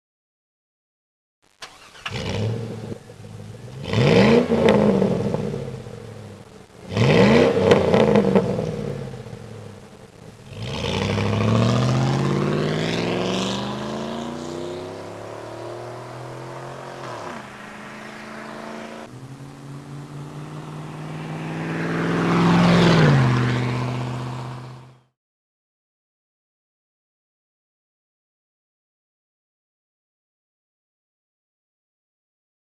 Sound Test
GlasspackSound.mp3